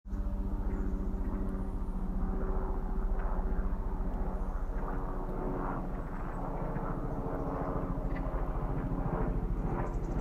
Sound 4 = Non-natural sound: Helicopter overhead